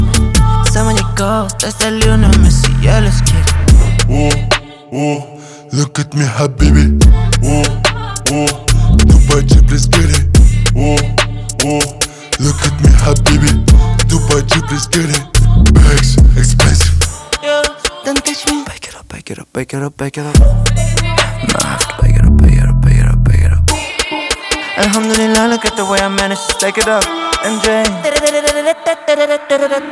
Жанр: Рэп и хип-хоп
# UK Hip-Hop